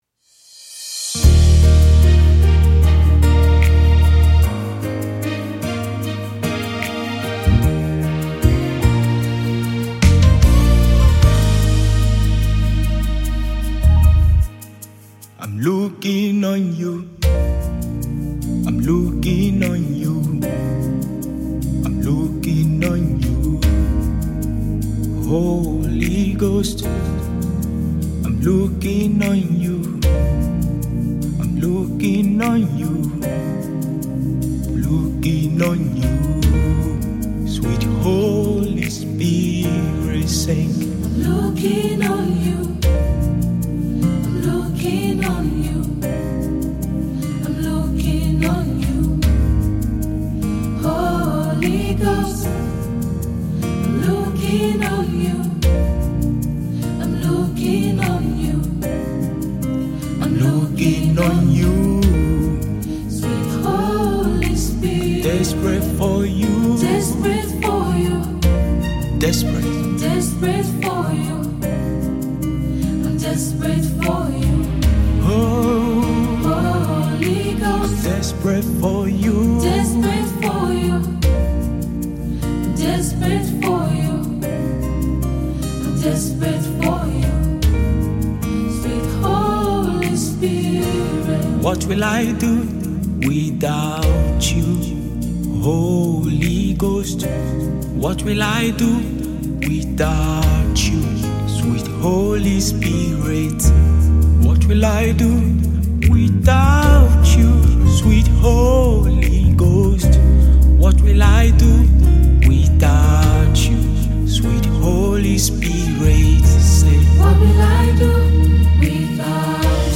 Fast rising Gospel Singer and songwriter